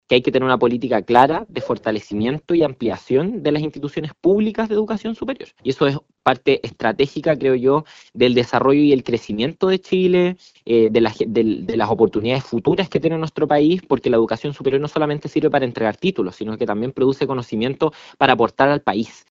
Por su parte, la diputada del Frente Amplio e integrante de la comisión de Educación de la Cámara, Emilia Schneider, aseguró que es necesario establecer una política clara de fortalecimiento y ampliación de la educación superior pública y de las instituciones.